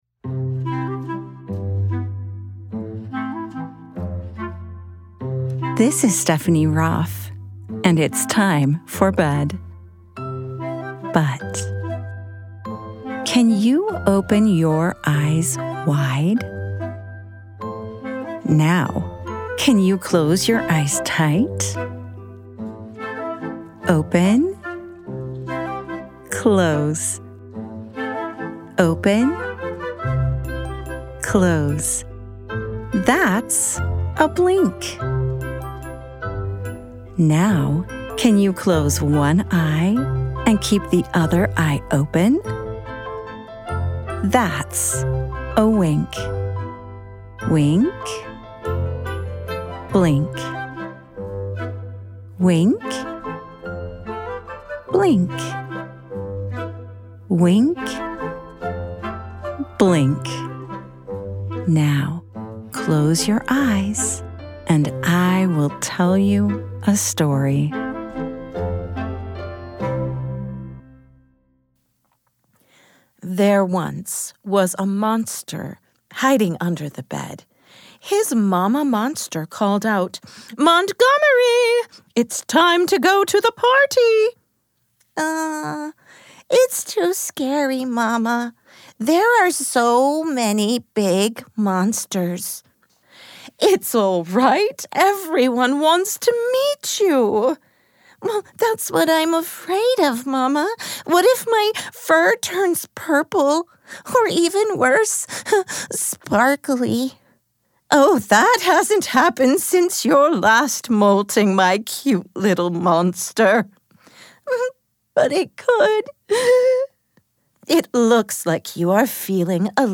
Montgomery the Monster: A Mindful Bedtime Story for Kids
@ wink-bedtime-stories Wink is a production of BYUradio and is always ad and interruption free.